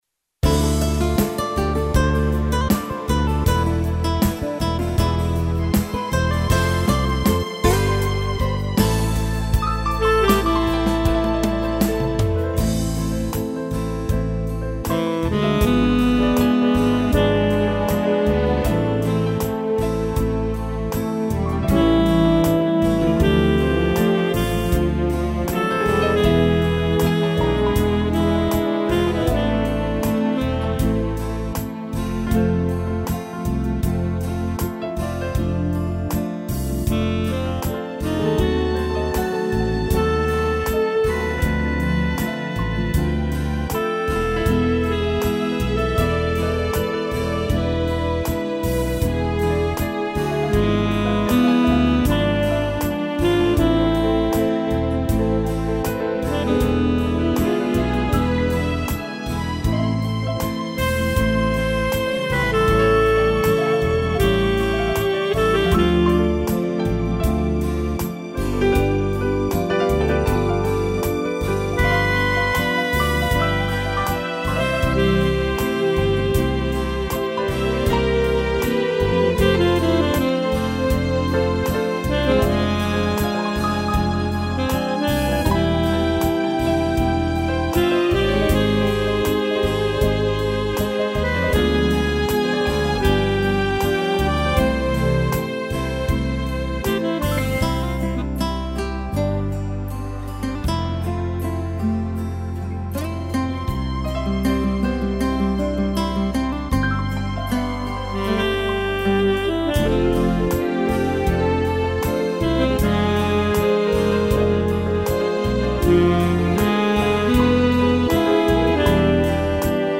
solo piano